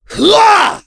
Kasel-Vox_Attack4.wav